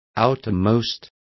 Complete with pronunciation of the translation of outermost.